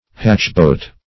Search Result for " hatch-boat" : The Collaborative International Dictionary of English v.0.48: Hatch-boat \Hatch"-boat`\ (h[a^]ch"b[=o]t`), n. (Naut.)